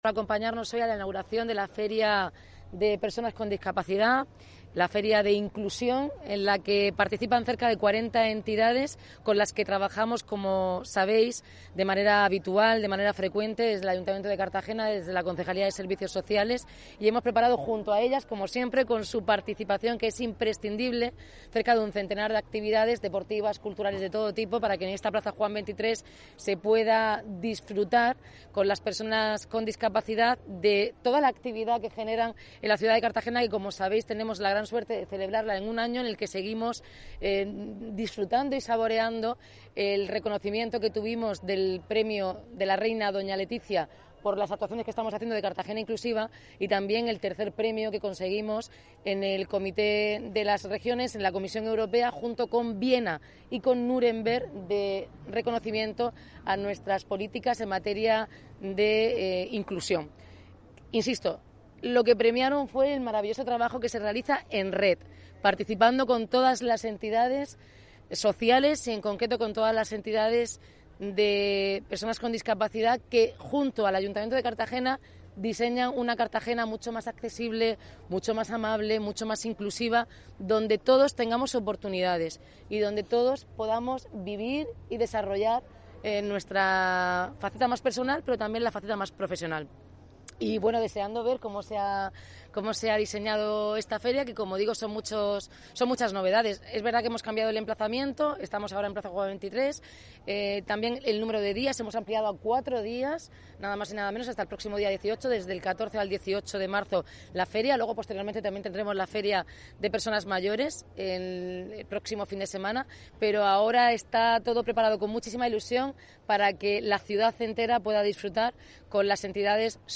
La alcaldesa ha señalado, durante la inauguración de la IV Feria de Asociaciones de Personas con Discapacidad, que esta iniciativa da respuesta a la demanda